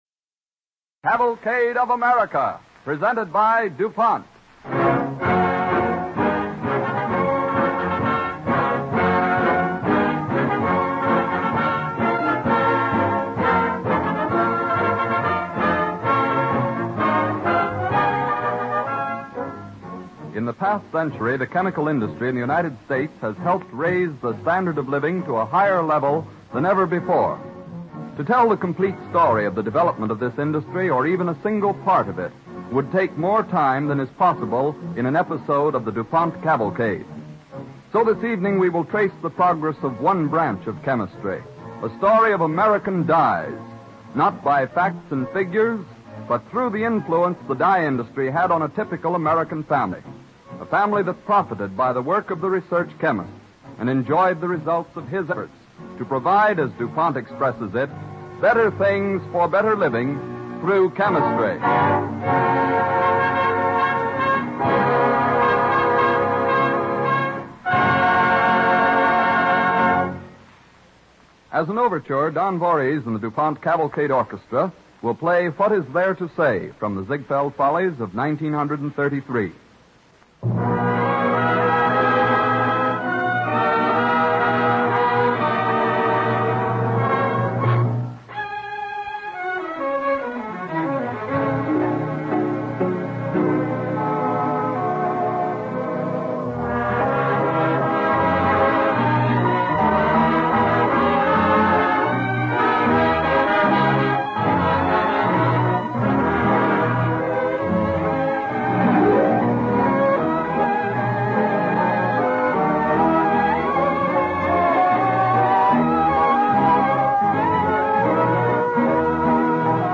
Cavalcade of America Radio Program
With announcer